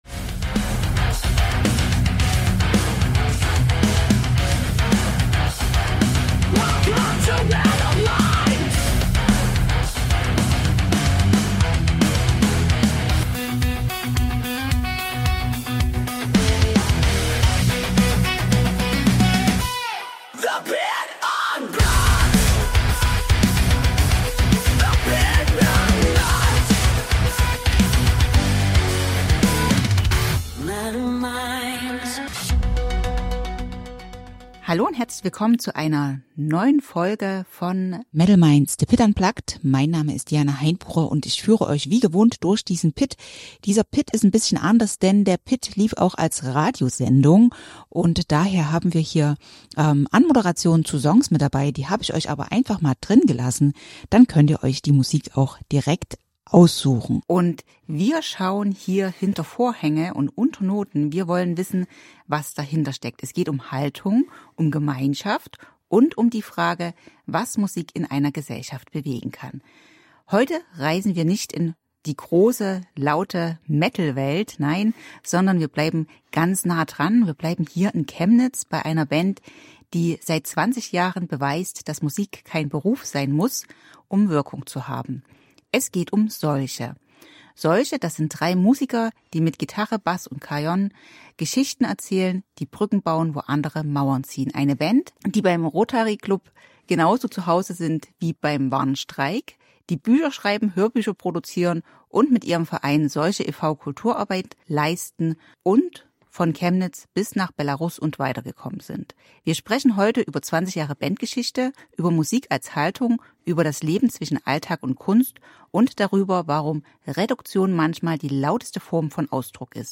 Wir sprechen über Neuanfänge, kreative Prozesse, Reisen nach Belarus, Litauen und bis nach Wladiwostok, über Reduktion als künstlerische Haltung und über eine Rechtsform, die in der Musikszene selten ist: den Kulturverein Solche e.V. Ein inspirierendes Gespräch über Kunst, Gemeinschaft und die Freiheit, Musik nach eigenen Regeln zu gestalten.